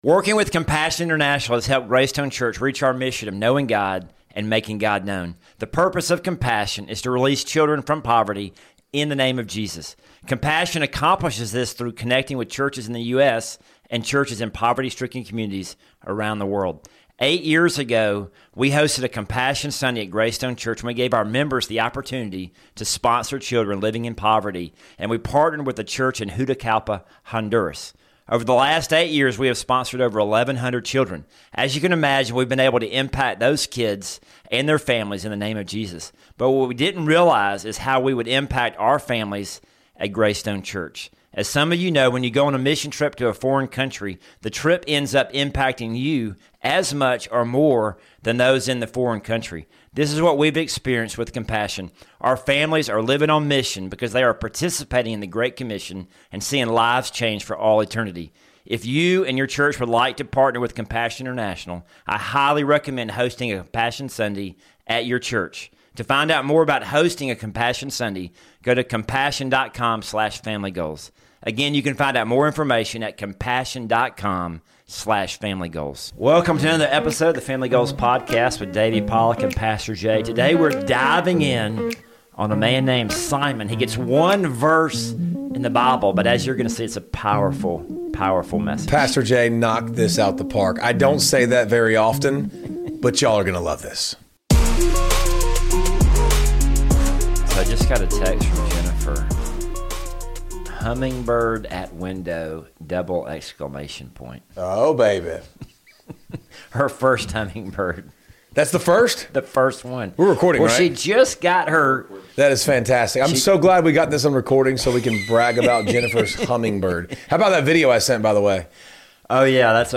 have a weekly conversation about God, Family, and Sports.